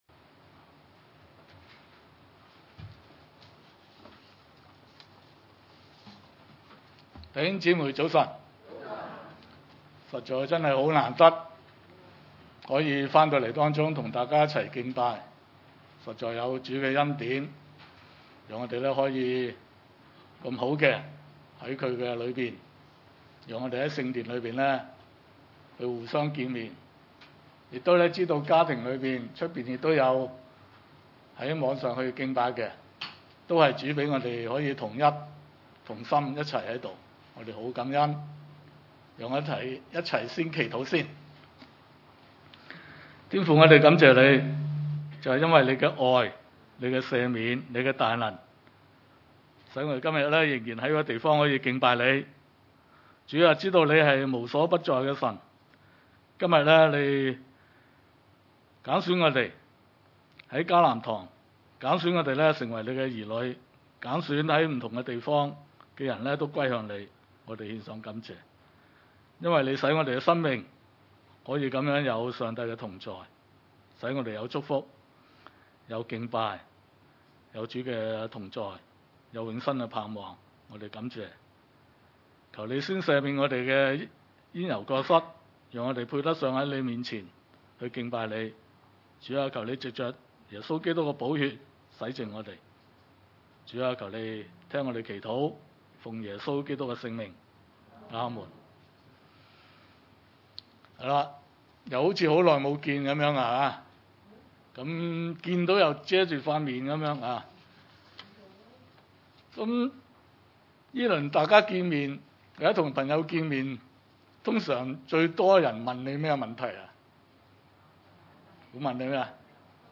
腓1:8—27 崇拜類別: 主日午堂崇拜 1:8 我 體 會 基 督 耶 穌 的 心 腸 、 切 切 的 想 念 你 們 眾 人 ． 這 是 神 可 以 給 我 作 見 證 的 。